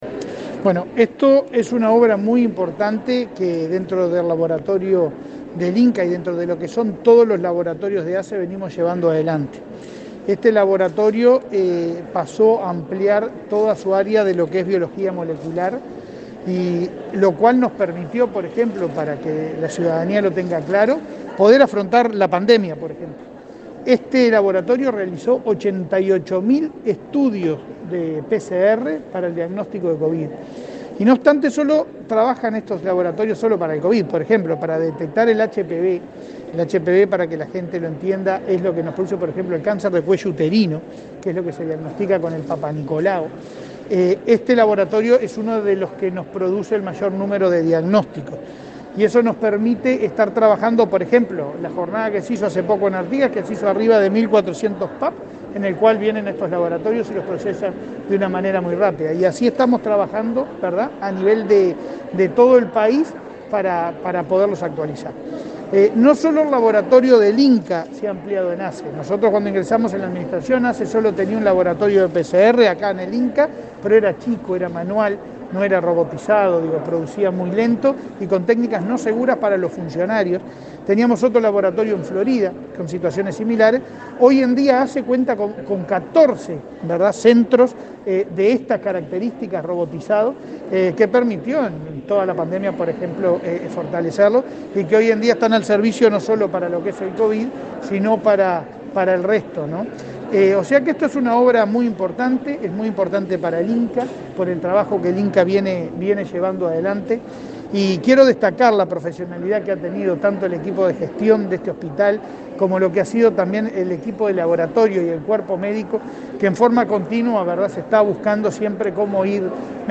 Declaraciones a la prensa del presidente de ASSE, Leonardo Cipriani
Declaraciones a la prensa del presidente de ASSE, Leonardo Cipriani 08/06/2022 Compartir Facebook X Copiar enlace WhatsApp LinkedIn El presidente de la Administración de los Servicios de Salud del Estado (ASSE), Leonardo Cipriani, participó en la inauguración de un laboratorio en el Instituto Nacional del Cáncer (INCA). Luego dialogó con la prensa.